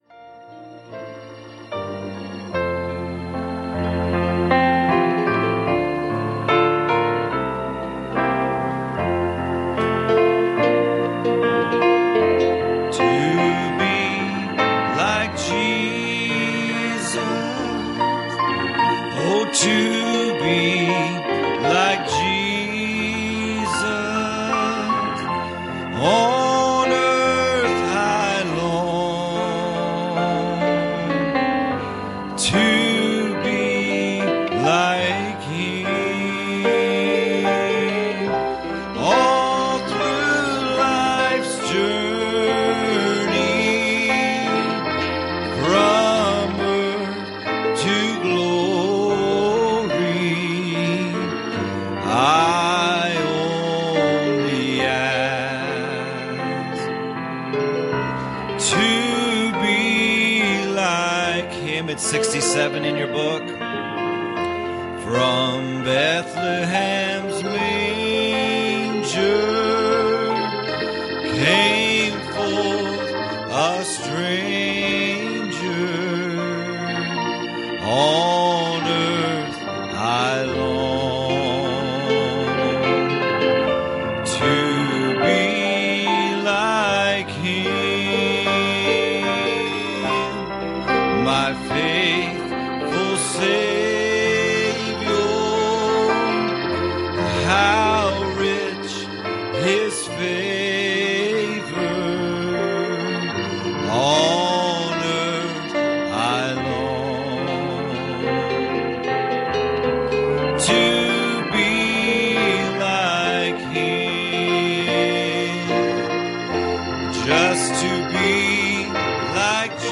Series: Sunday Morning Services
Service Type: Sunday Morning